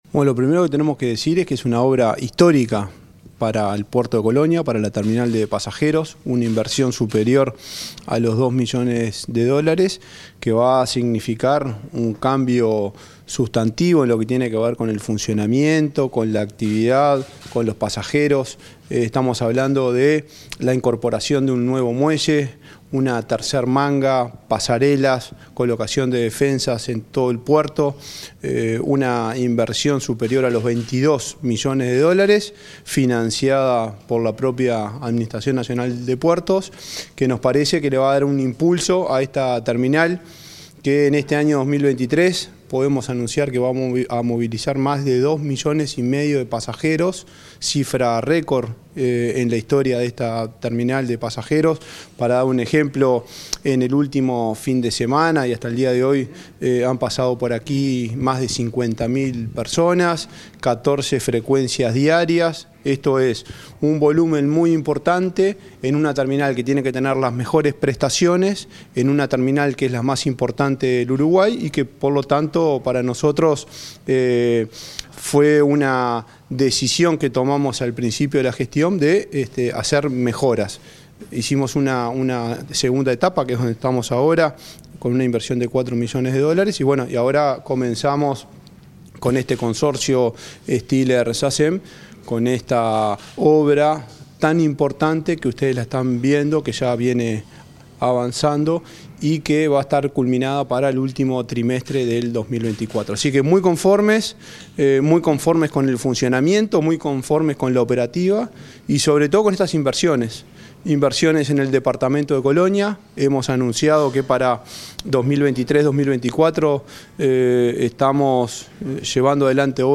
Entrevista al presidente de la ANP, Juan Curbelo